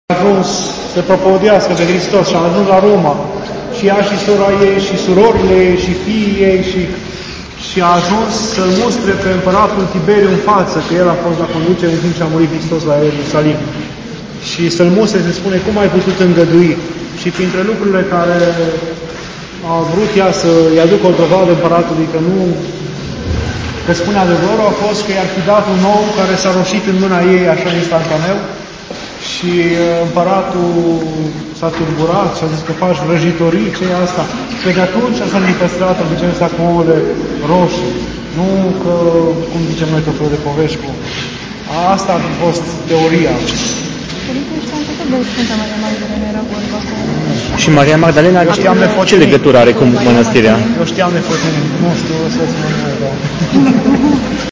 Mănăstirea rusă ,,Sf. Maria-Magdalena”, despre Sf Fotini